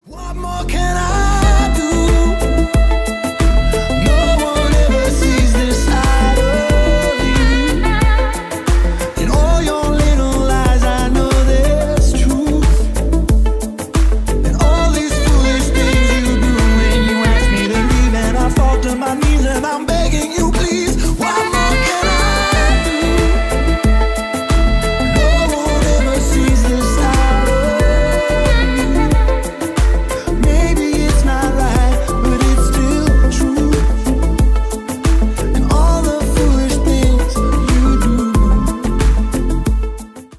Танцевальные
спокойные